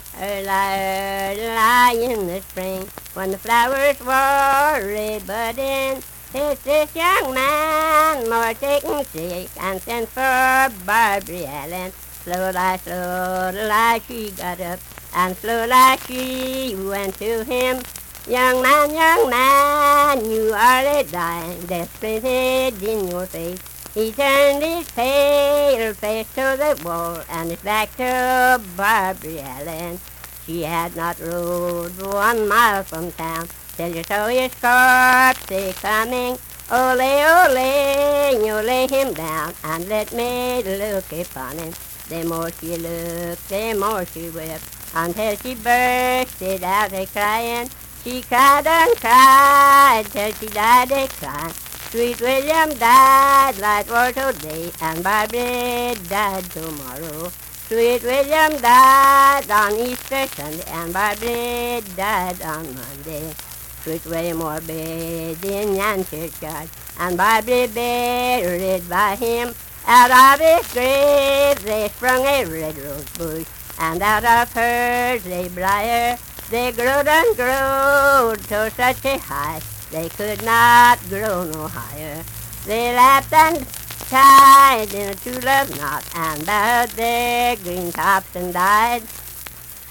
Unaccompanied vocal music
Verse-refrain 6(4-6).
Voice (sung)